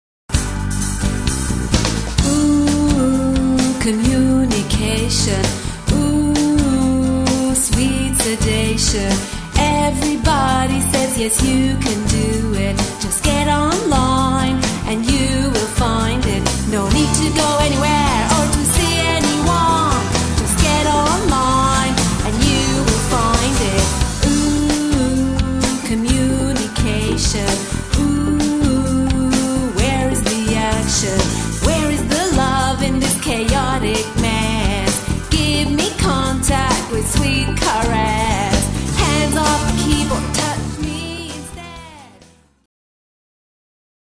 Category: Pop